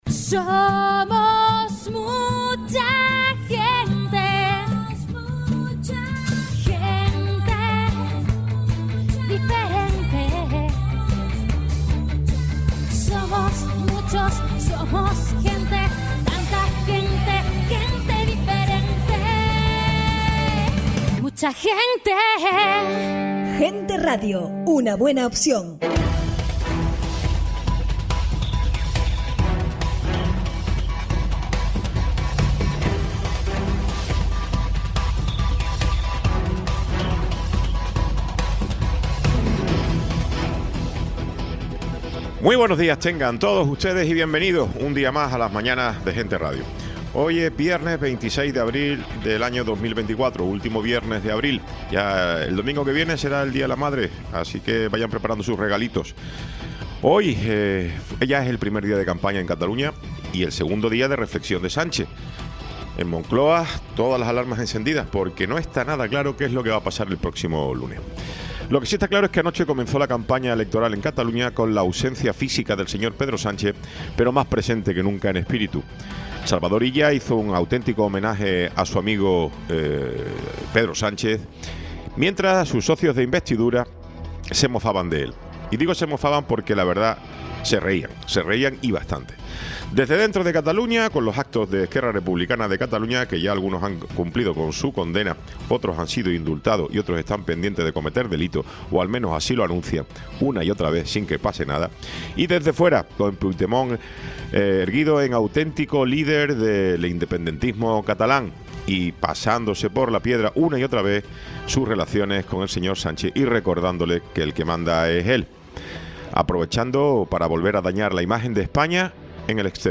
Programa sin cortes